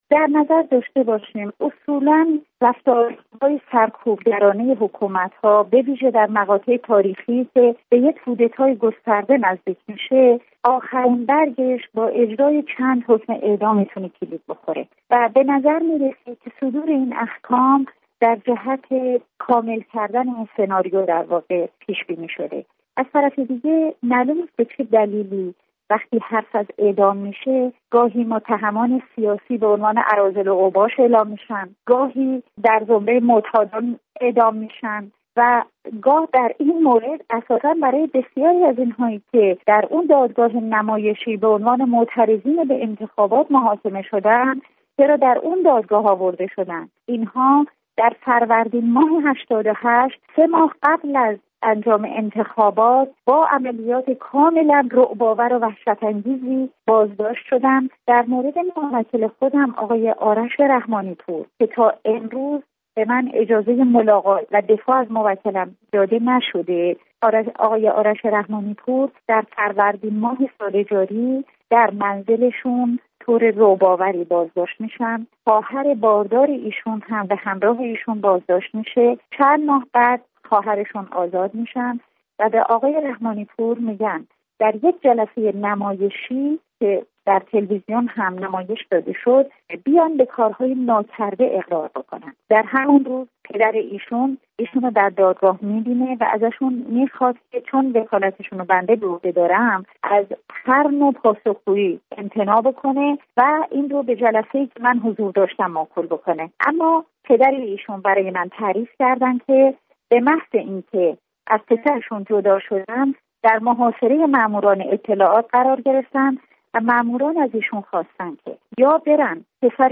گفت‌وگو با نسرین ستوده وکیل دادگستری در تهران